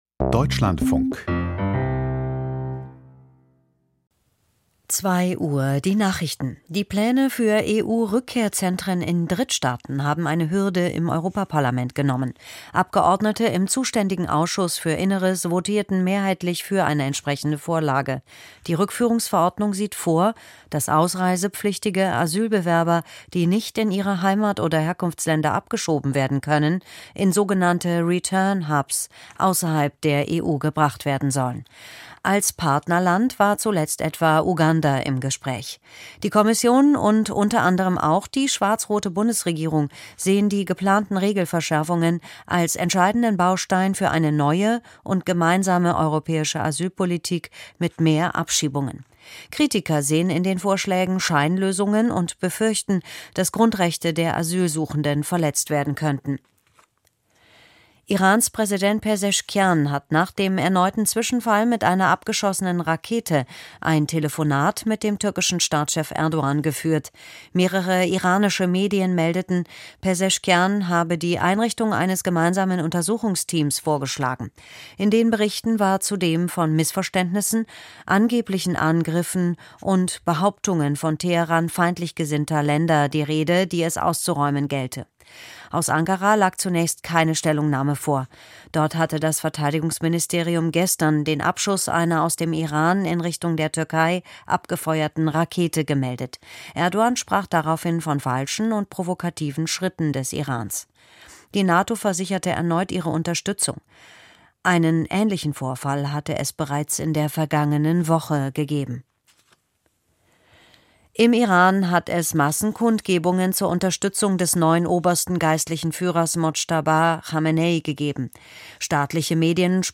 Die Nachrichten vom 10.03.2026, 02:00 Uhr
Aus der Deutschlandfunk-Nachrichtenredaktion.